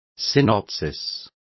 Complete with pronunciation of the translation of synopsis.